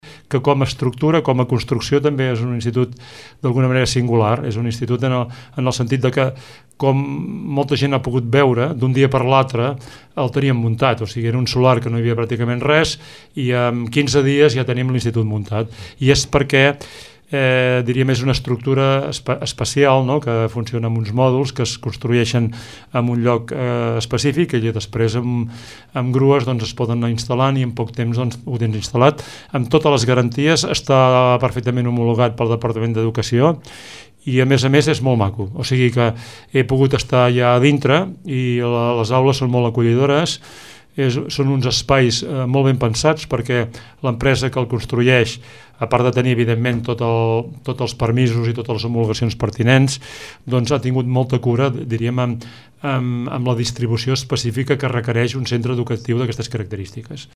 El regidor d’ensenyament, Àngel Pous, parla d’una construcció singular, amb totes les garanties de seguretat i confort.